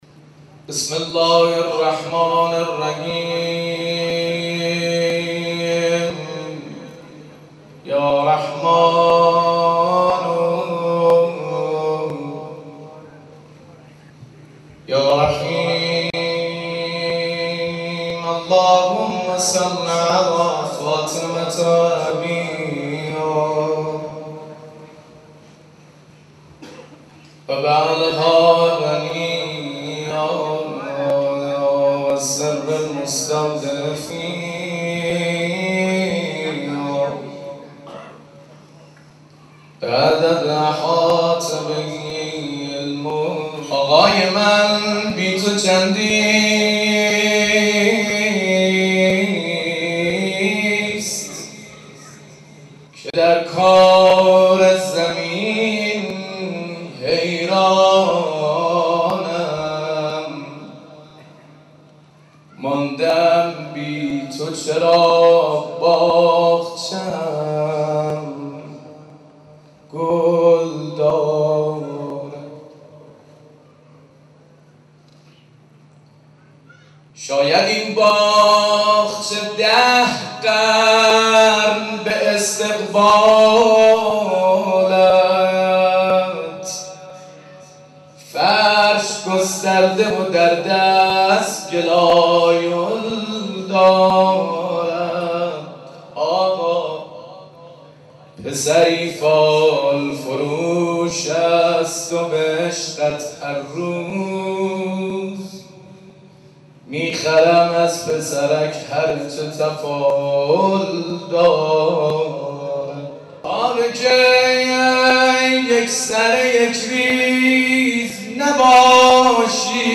مناسبت : ولادت حضرت فاطمه‌ زهرا سلام‌الله‌علیها
قالب : مدح سرود